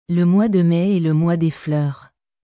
Le mois de mai est le mois des fleursル モワ ドゥ メ エ ル モワ デ フルール